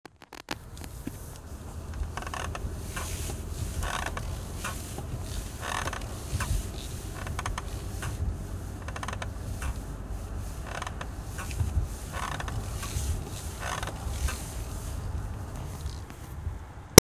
Video: Fahrersitz knarzt, was kann das sein?
das Video an - mein Fahrersitz macht seit ein paar Wochen zunehmend nervige Knarzgeräusche auf der linken Seite der Sitzfläche beim Kurvenfahren.
In dem Video fahre ich bewusst Schlangenlinien, um das Geräusch zu provozieren.